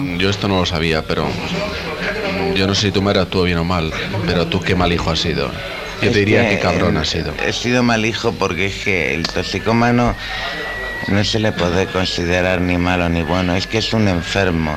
Conversa amb un toxicòman
Info-entreteniment